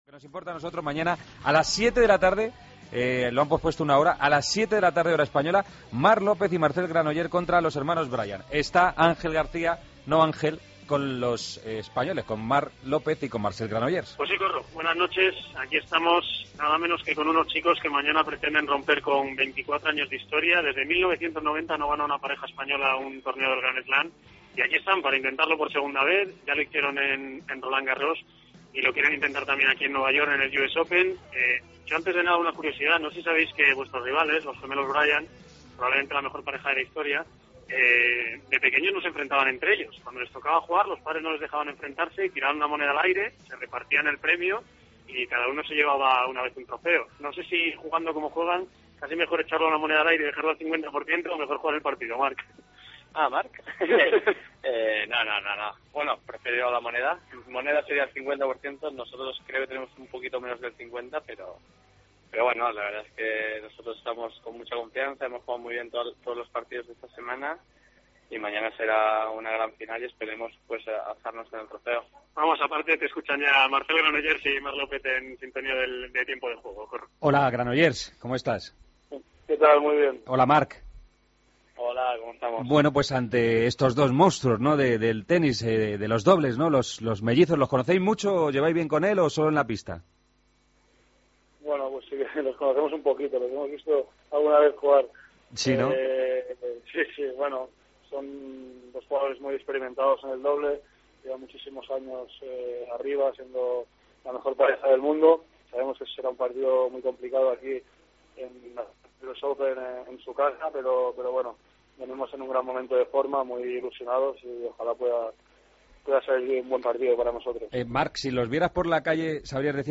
Entrevista a Marc López y Marcel Granollers, en Tiempo de Juego